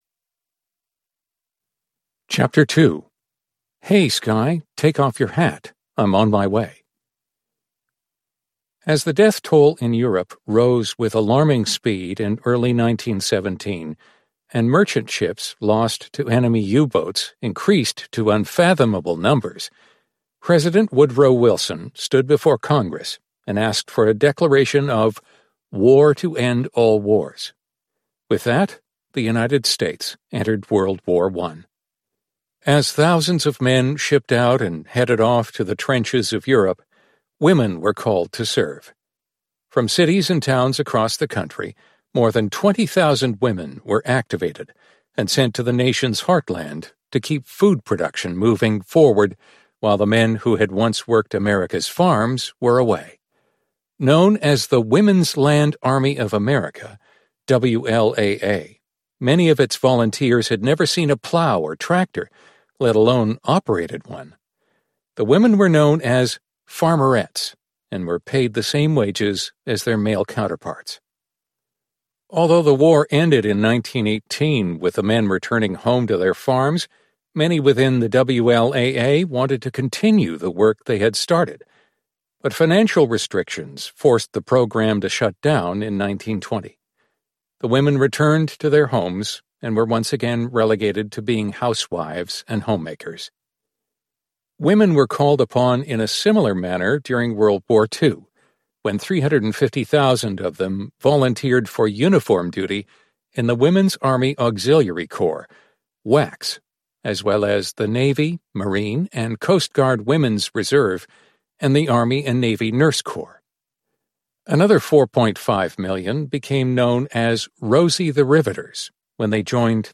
Space Oddities Audio Book Preview